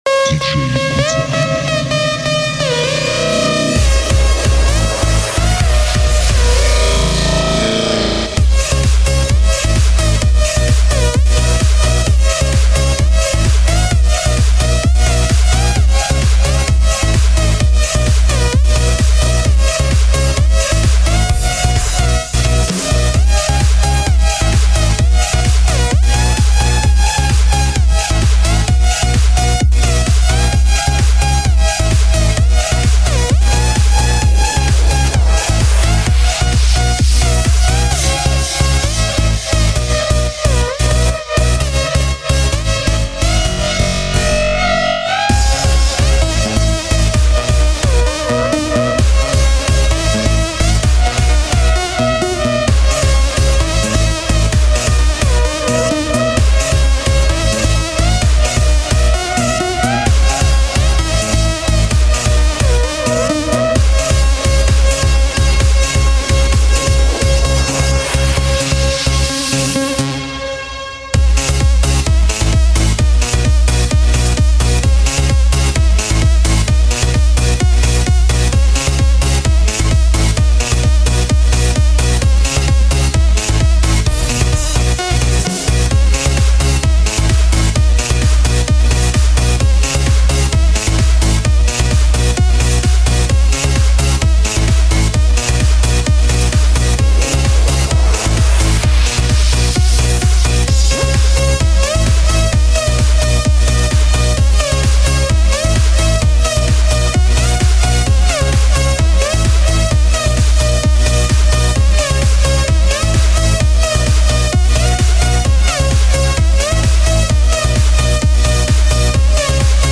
Жанр: House - Electro